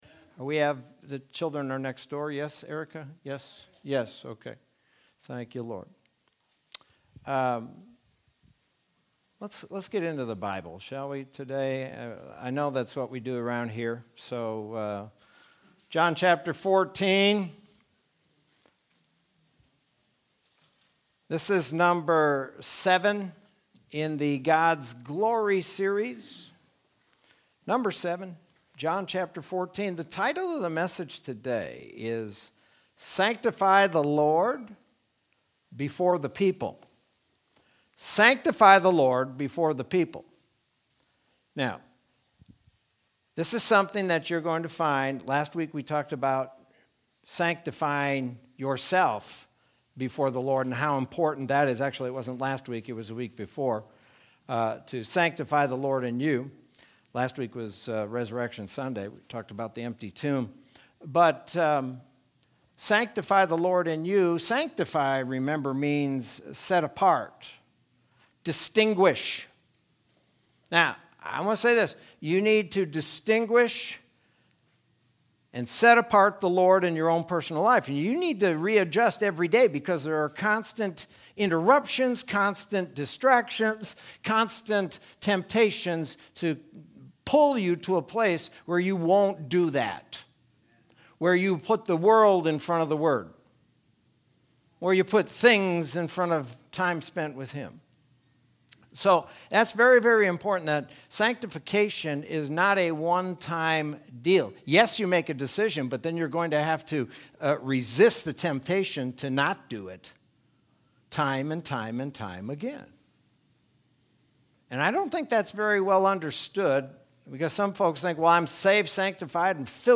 Sermon from Sunday, April 11th, 2021.